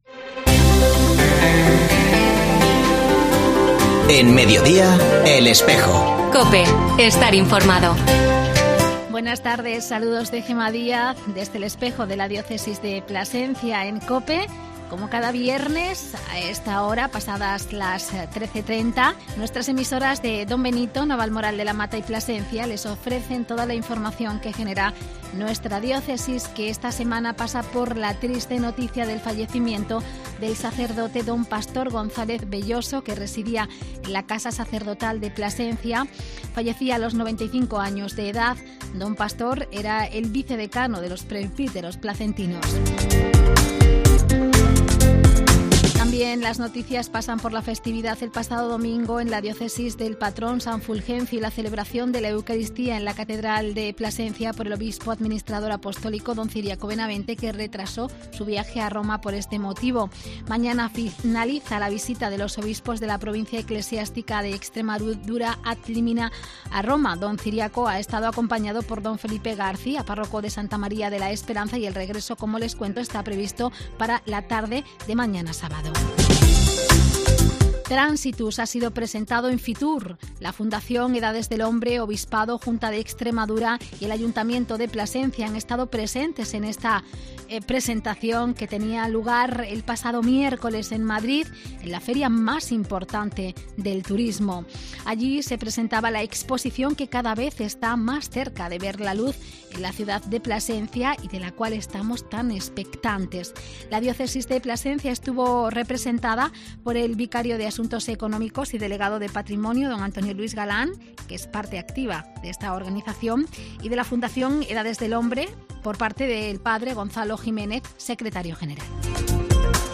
AUDIO: En la edición de este viernes, en El Espejo de La Iglesia escuchamos un amplio reportaje realizado en el Seminario Diocesano de Plasencia...